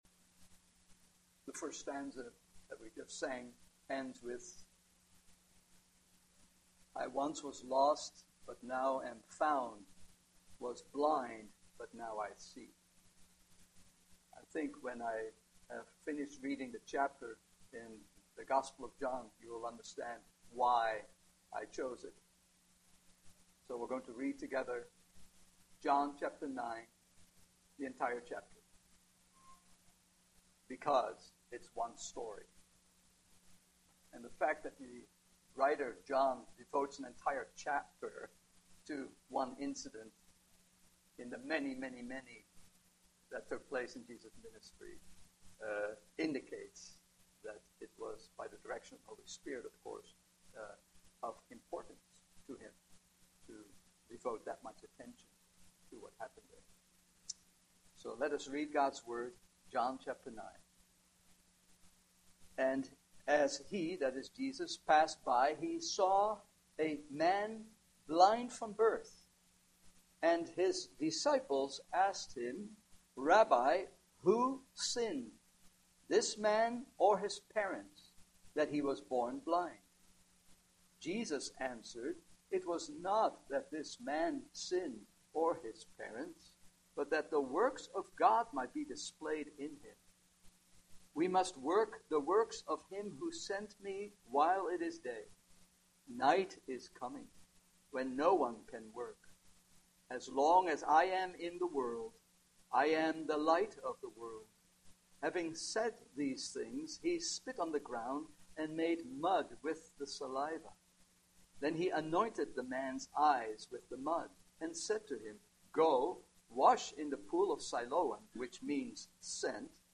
Fruit of the Spirit Passage: John 9 Service Type: Evening Service Topics